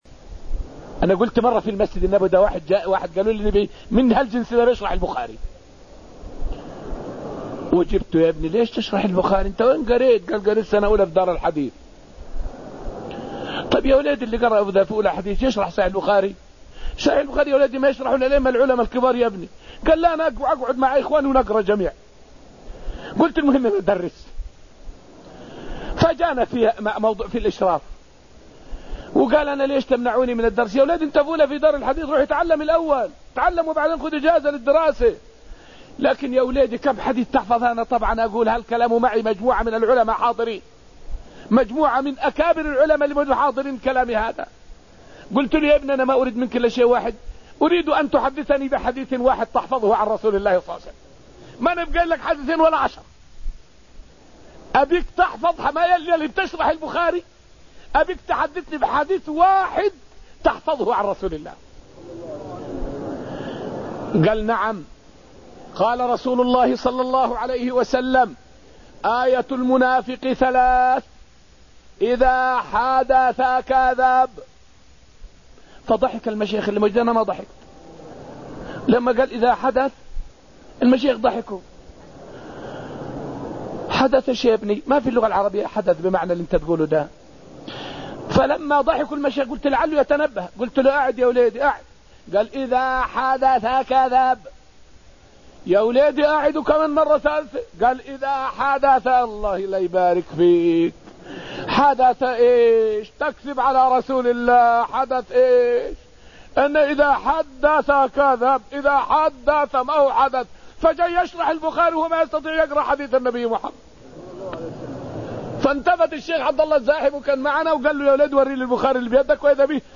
فائدة من الدرس السابع من دروس تفسير سورة الحديد والتي ألقيت في المسجد النبوي الشريف حول تصحيح مفهوم استخلاف الله للإنسان في الأرض.